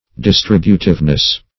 Distributiveness \Dis*trib"u*tive*ness\, n.